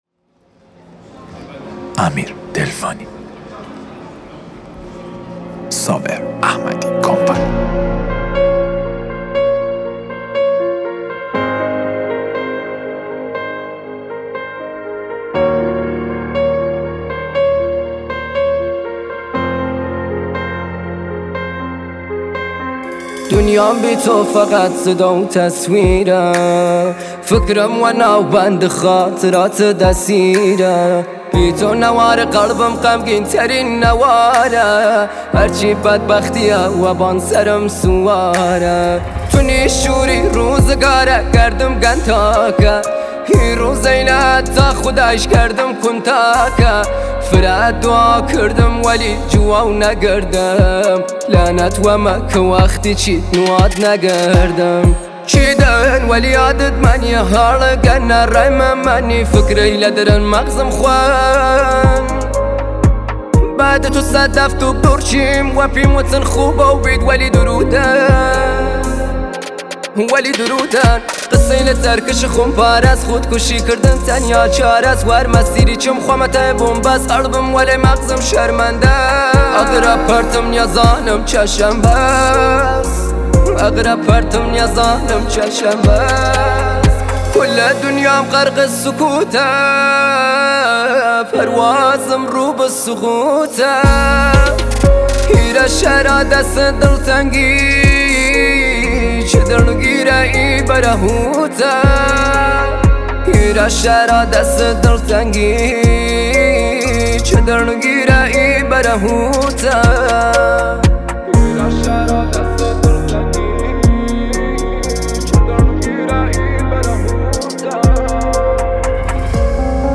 ریمیکس تند بیس دار رپی ترکیبی ترند اینستا